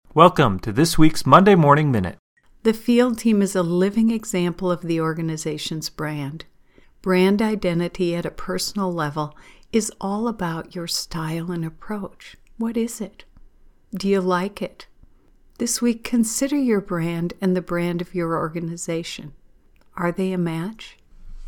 Studio version: